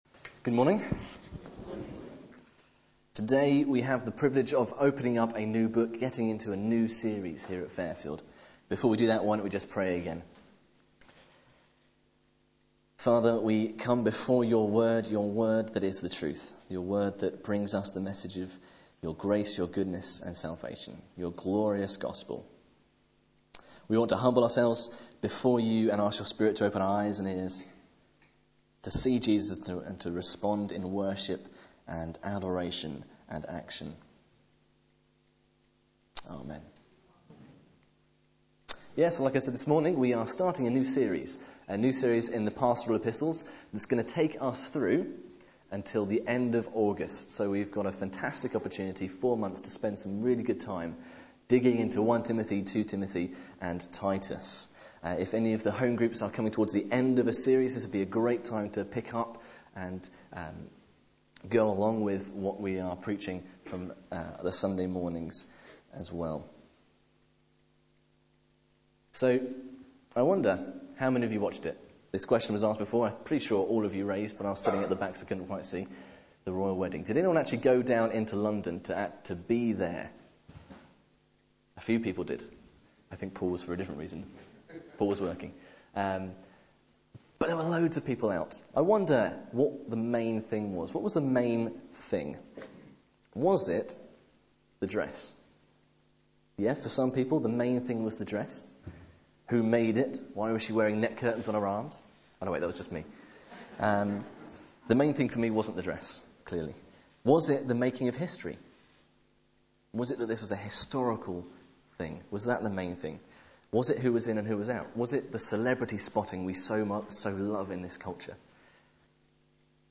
Media for Sunday Service on Sun 01st May 2011 11:00
Passage: 1 Timothy 1.1-11 Series: The Pastoral Epistles Theme: Sermon